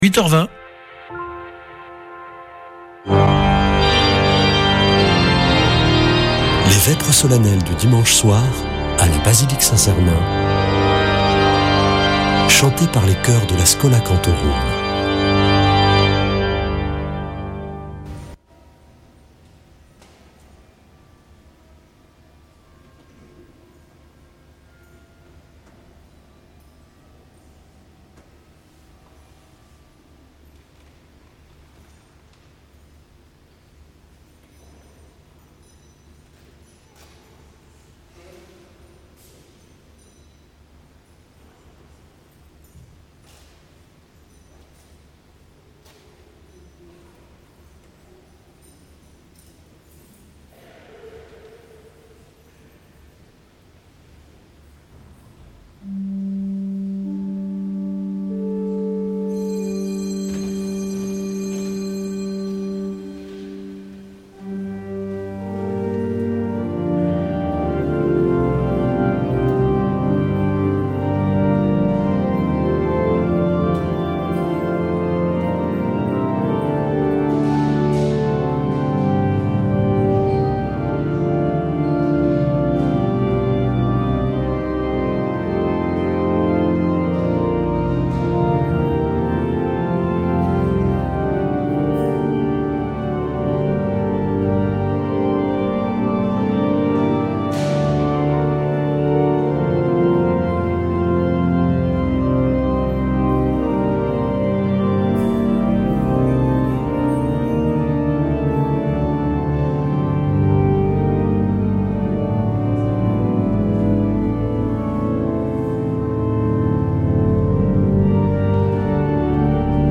Vêpres de Saint Sernin du 26 oct.
Accueil \ Emissions \ Foi \ Prière et Célébration \ Vêpres de Saint Sernin \ Vêpres de Saint Sernin du 26 oct.
Une émission présentée par Schola Saint Sernin Chanteurs